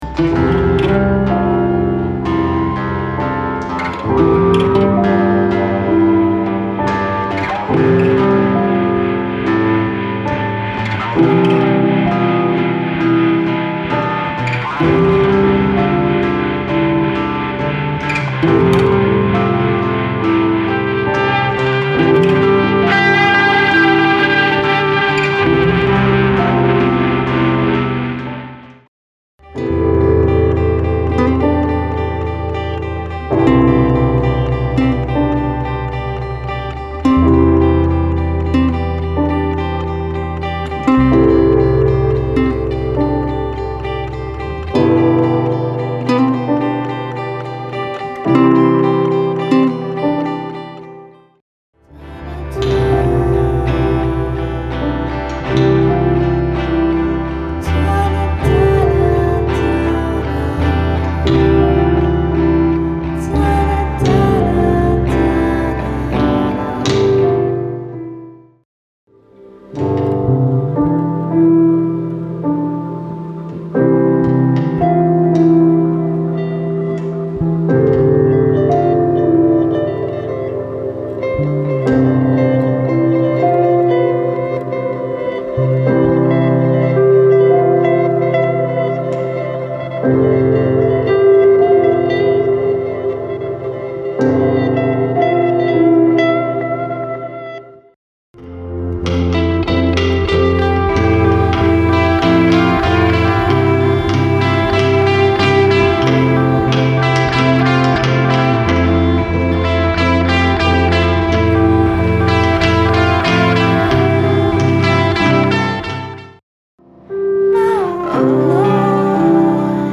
Genres: Ambient Post Rock, Acoustic, Jam Sessions